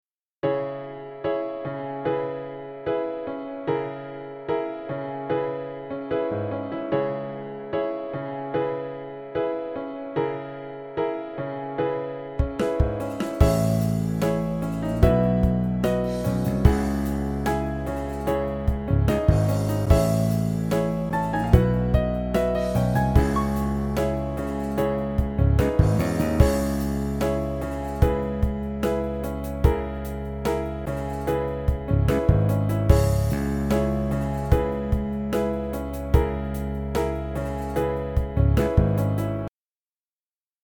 Note that I haven't really bothered making sure that the bounces are great, so there are a few clicks here and there.
Another pop/rock track, kinda in the style of The Whitlams. Fun with an upright piano. :)